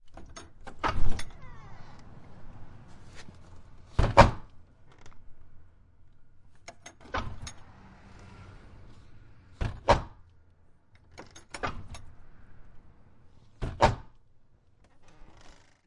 随机的 " 木质的门 粗糙的公寓沉重的后门到阳台的推关闭捕捉吱吱作响，与闩锁
描述：门木多节公寓沉重的后门到阳台推闭合捕捉吱吱声，与latch.flac